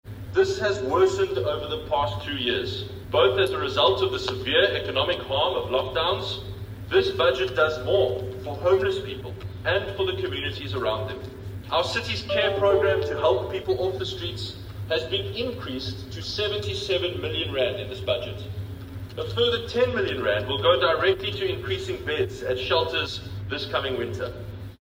Hill-Lewis, during his first budget speech this week, said he believes Cape Town must become a more caring city that does more to protect the vulnerable.